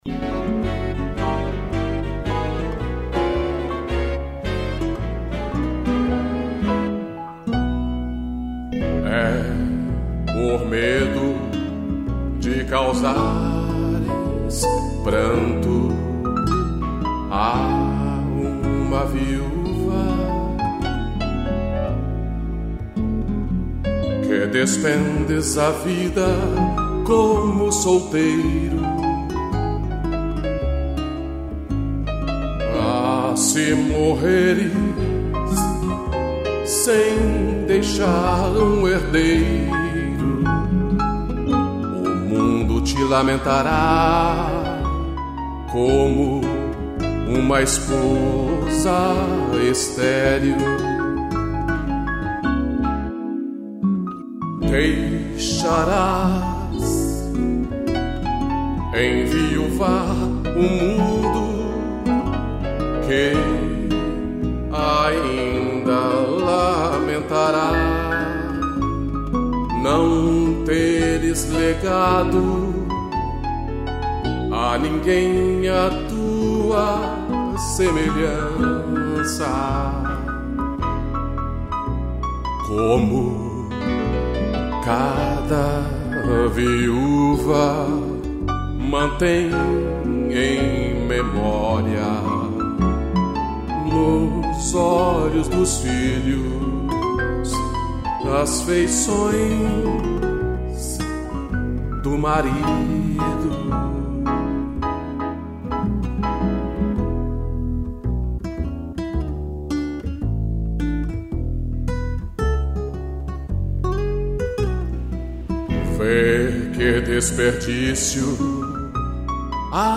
interpretação e violão
piano